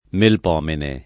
vai all'elenco alfabetico delle voci ingrandisci il carattere 100% rimpicciolisci il carattere stampa invia tramite posta elettronica codividi su Facebook Melpomene [ melp 0 mene ; raro, alla greca, melpom $ ne ] pers. f. mit.